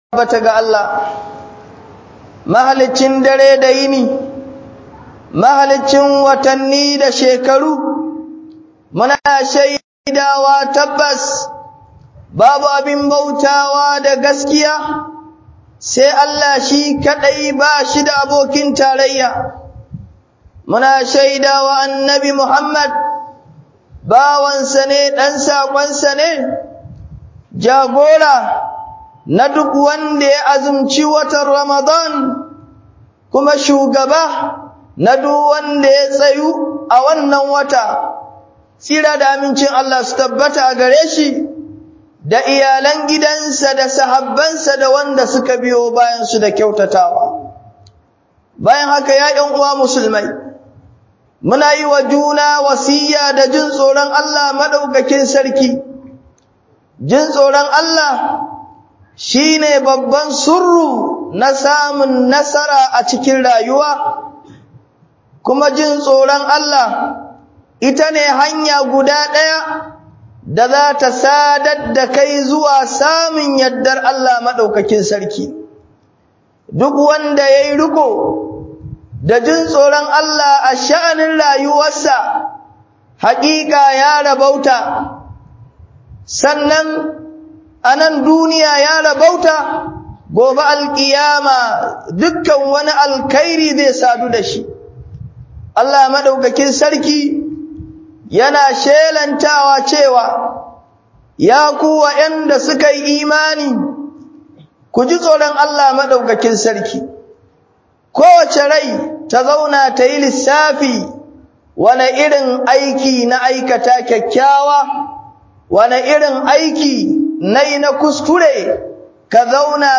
HUƊUBOBI